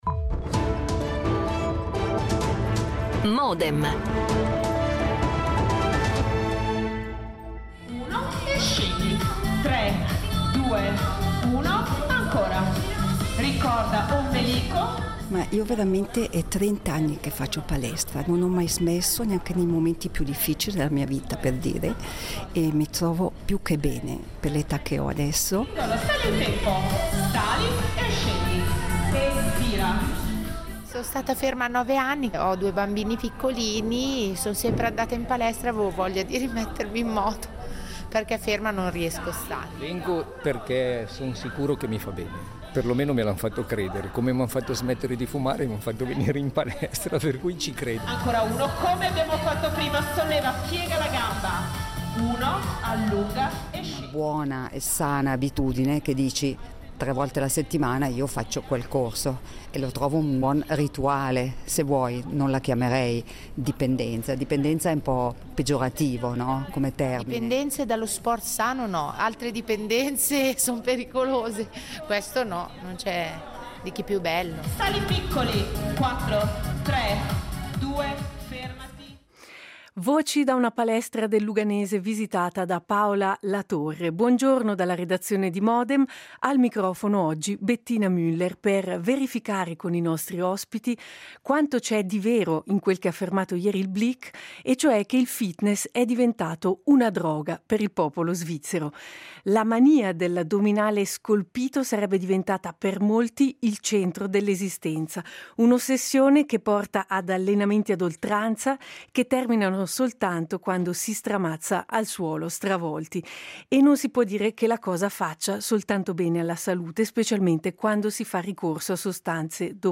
istruttore Scopri la serie Modem L'attualità approfondita, in diretta, tutte le mattine, da lunedì a venerdì https